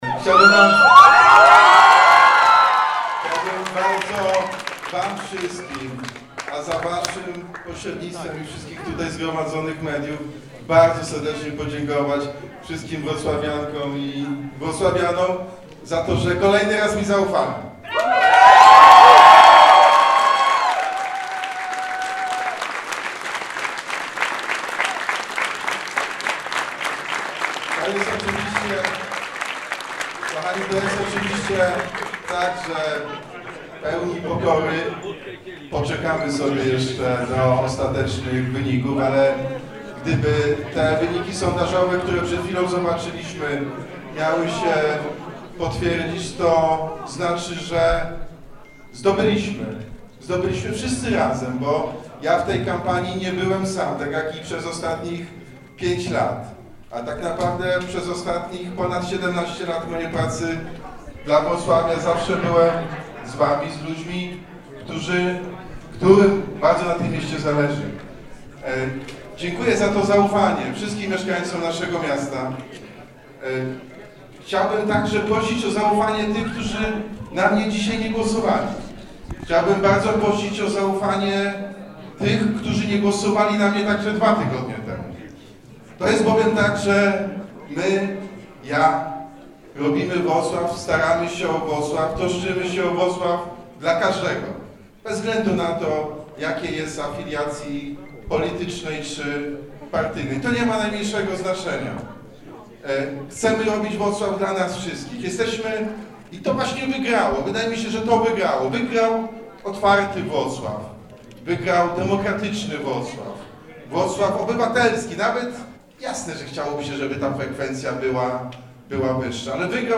W sztabie prezydenta Jacka Sutryka po ogłoszeniu wyników radość.